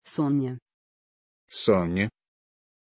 Pronunciation of Sonni
pronunciation-sonni-ru.mp3